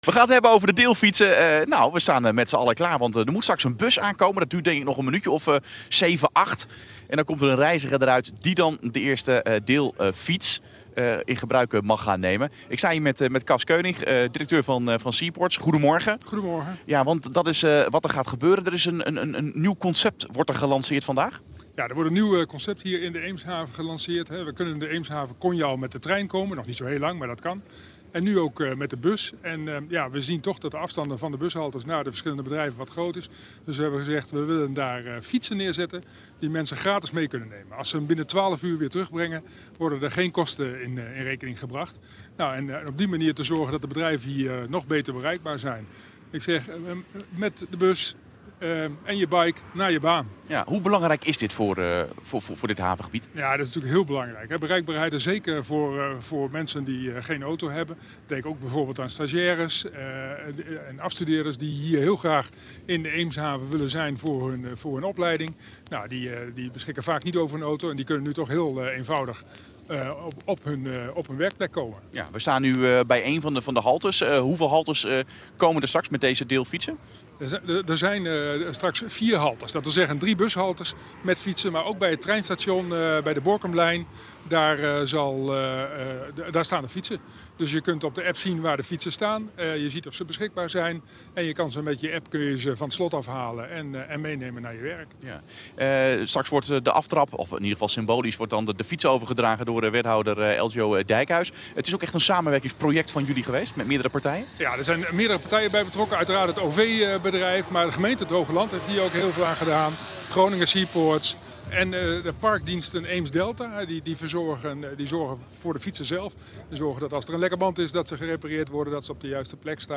RTV Noord: interview